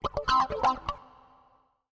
ELECGUIT056_HOUSE_125_A_SC2(R).wav